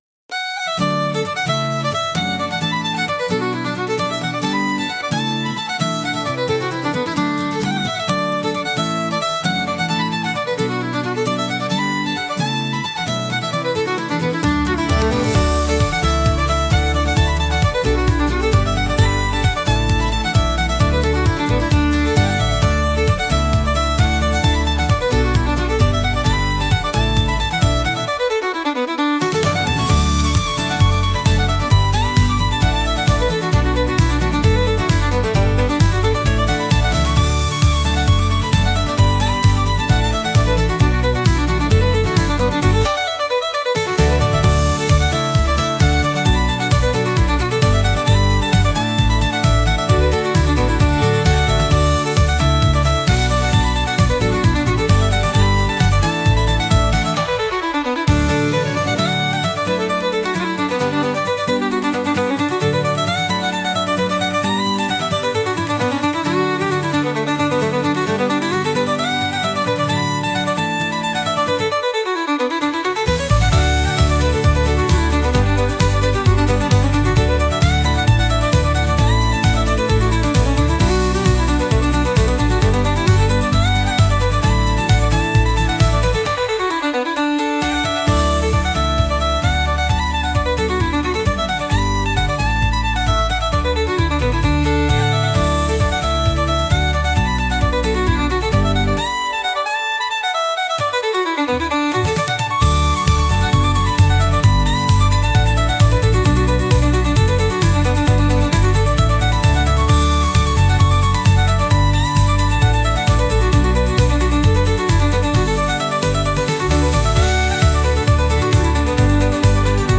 お祭りで楽しい雰囲気のケルト音楽です。